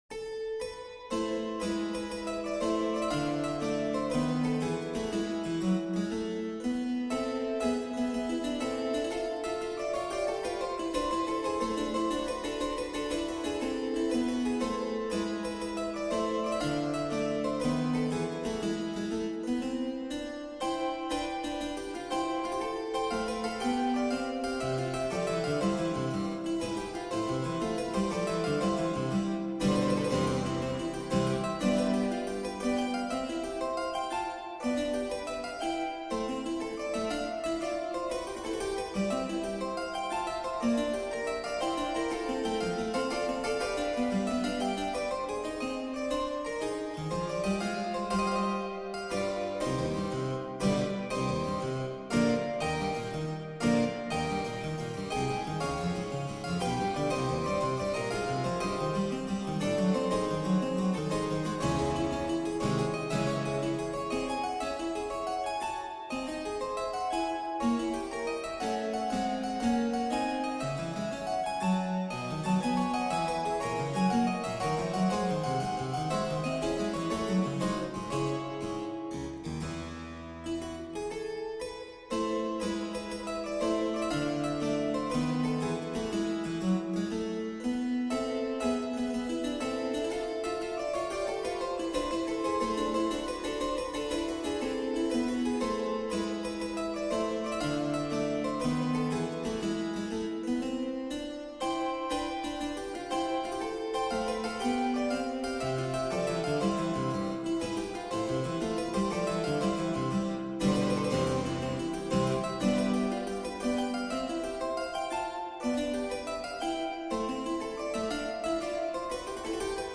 Sonata allegro exercise - Piano Music, Solo Keyboard - Young Composers Music Forum